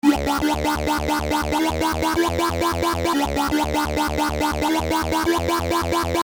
标签： 165 bpm Drum And Bass Loops Bass Loops 1.04 MB wav Key : Unknown
声道立体声